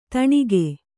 ♪ taṇige